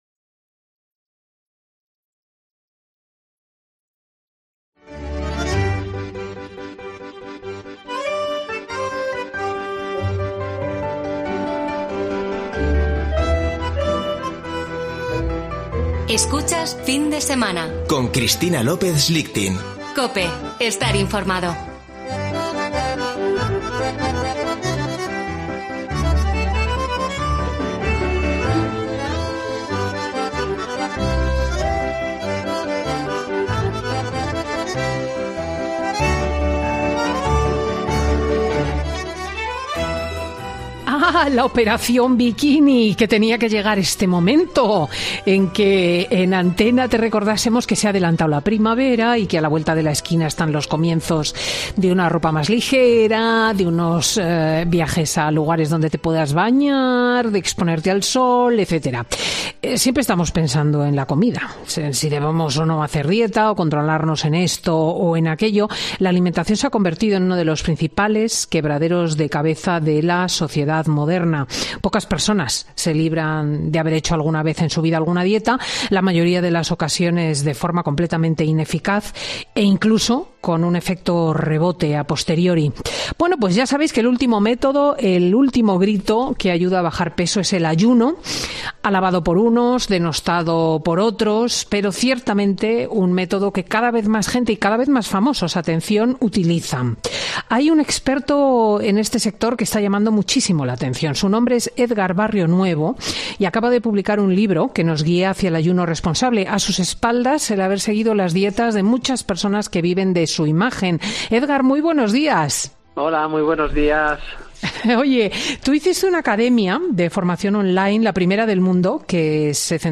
experto en nutrición, cuenta en Fin de Semana con Cristina por qué dejar de comer durante más horas de lo habitual no tiene por qué...